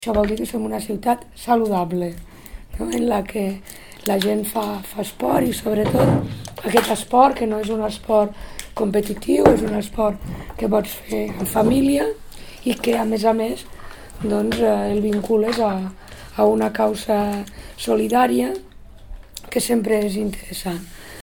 tall-de-veu-de-la-tinent-d2019alcalde-montse-parra-sobre-la-cursa-sed